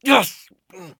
pain_6.ogg